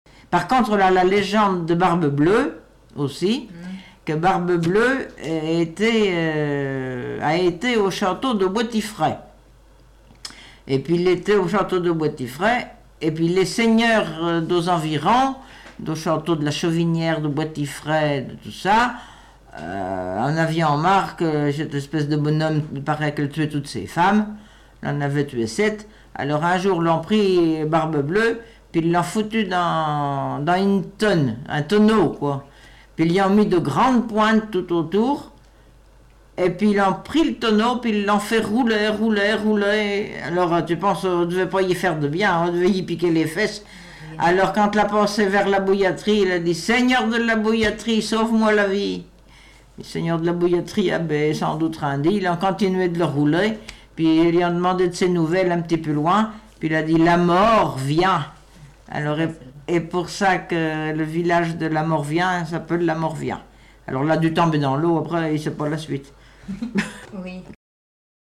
Genre légende
Enquête Arexcpo en Vendée-Association Joyeux Vendéens
Catégorie Récit